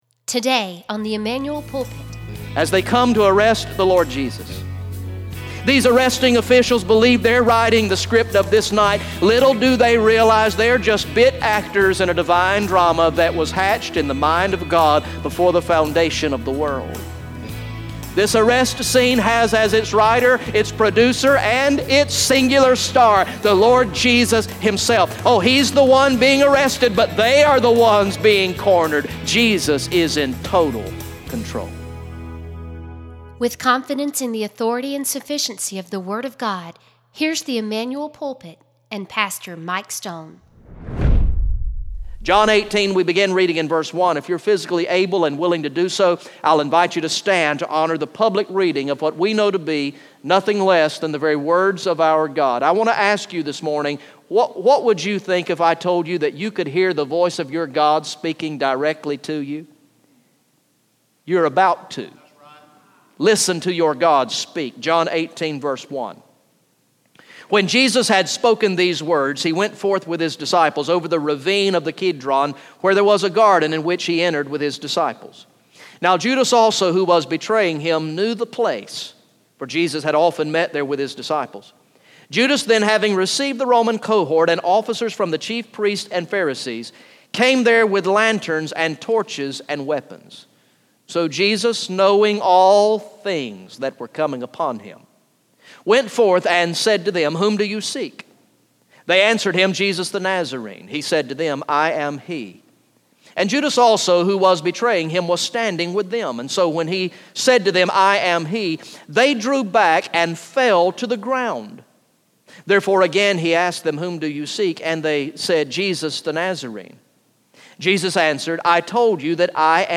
Message #67 from the sermon series through the gospel of John entitled "I Believe" Recorded in the morning worship service on Sunday, July 31, 2016